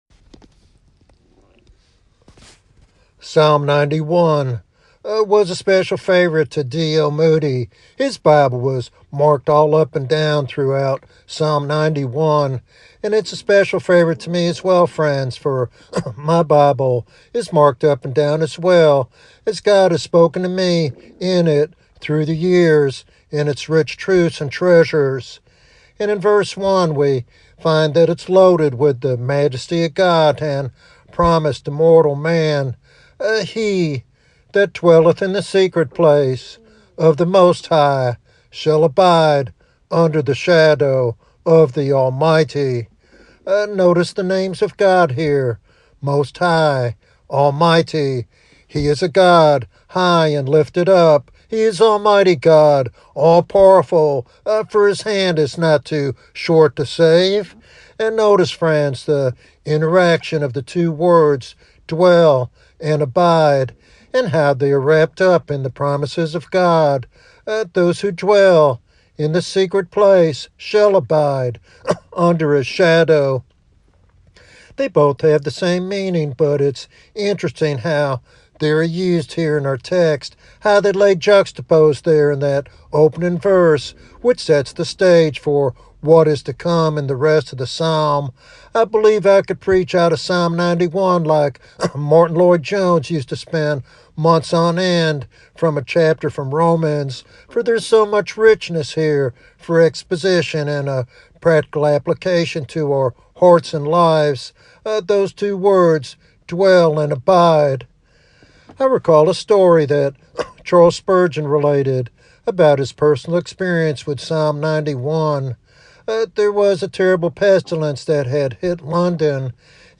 This sermon invites listeners to experience the peace and refuge found only in the secret place of the Most High.